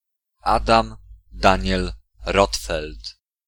Adam Daniel Rotfeld (Polish pronunciation: ['adam ˈdaɲɛl ˈrɔtfɛlt]
Pl-Adam_Daniel_Rotfeld.ogg.mp3